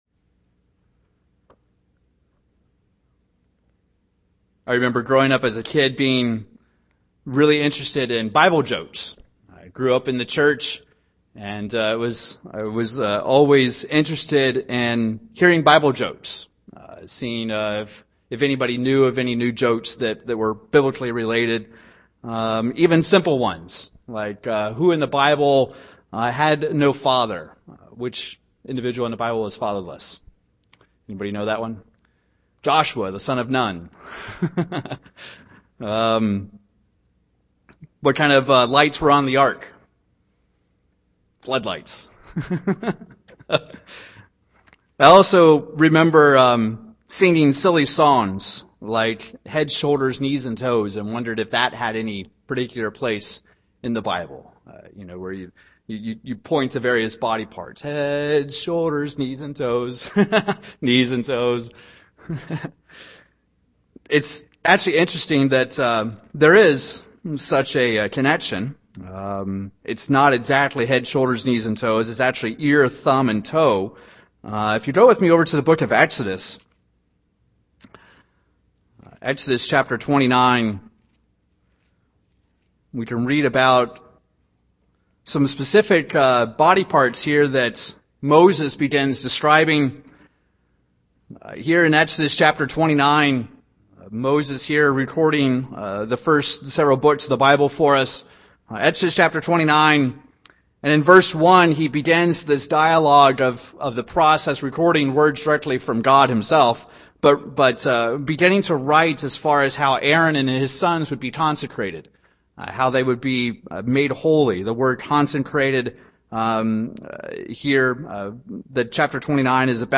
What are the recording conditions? Given in Wichita, KS